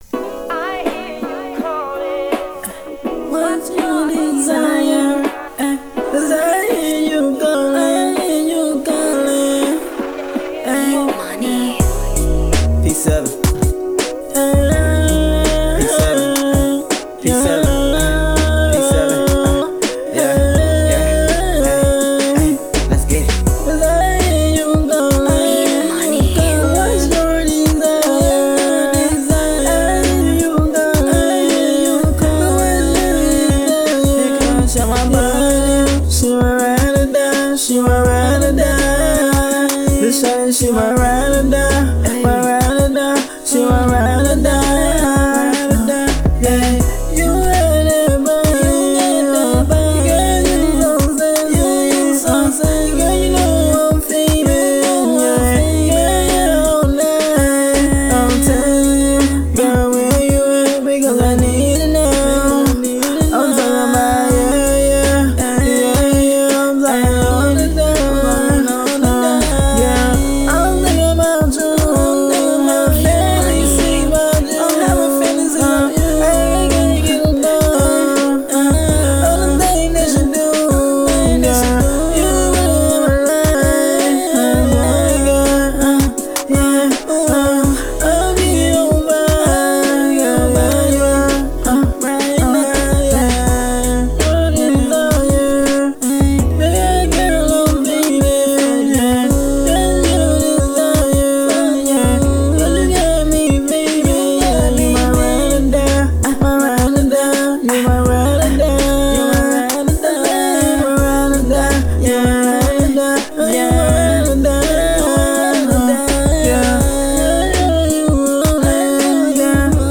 Alternative